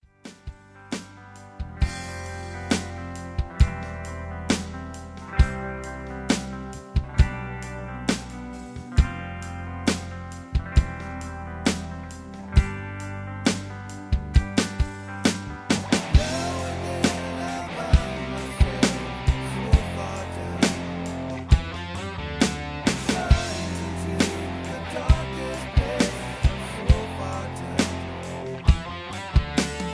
Just Plain & Simply "GREAT MUSIC" (No Lyrics).